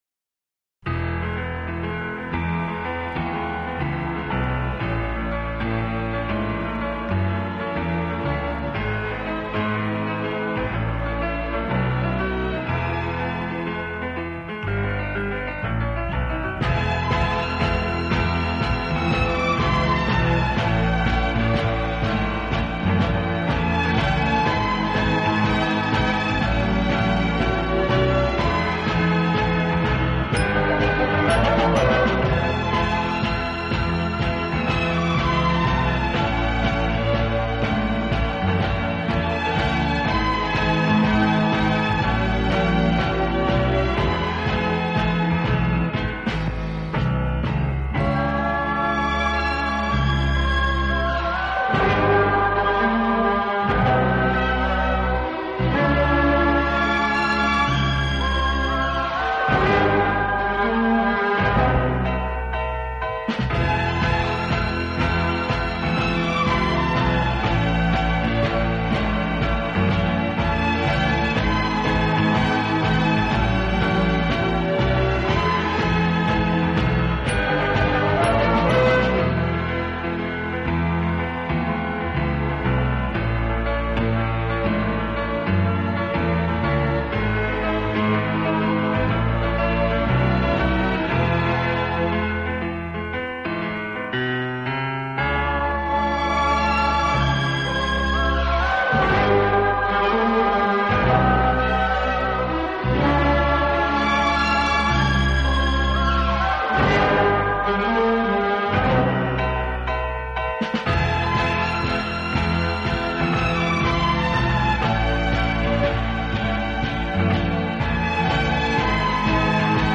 Recorded In France
Vinyl Rip
体，曲风浪漫、优雅，令人聆听時如感轻风拂面，丝丝柔情触动心扉，充分领略